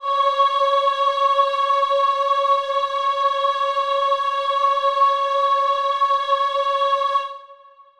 C#5.wav